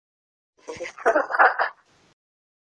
Звуки бабки Granny
Вы можете скачать её леденящий душу смех, скрип дверей, удары молотка и другие жуткие звуковые эффекты в высоком качестве.